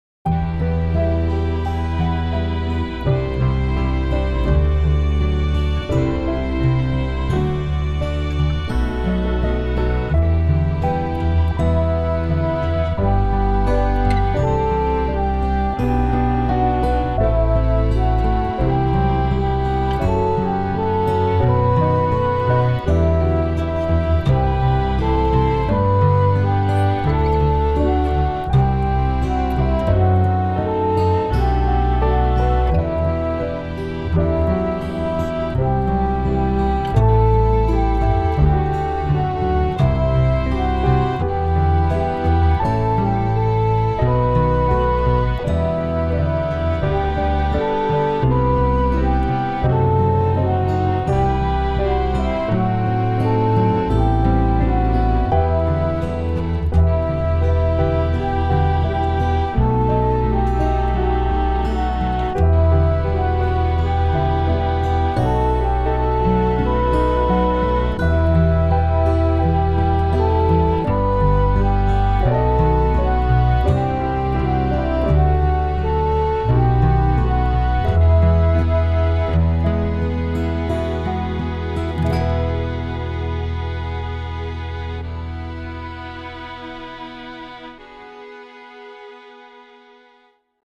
The effect here is also stark and cinematic.